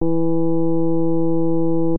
А я тут с помощью мельдовского сатуратора сделал из синуса концентрат того, что слышно на тех саксах. На этом звуке при инверсии аж будто меняется его высота.
Вложения Sine 5th Harm.mp3 Sine 5th Harm.mp3 80,7 KB · Просмотры: 558